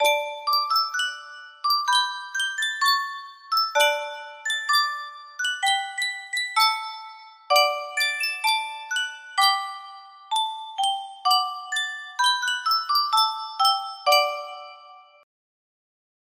Yunsheng Music Box - Vivaldi Rosmira 5937 music box melody
Full range 60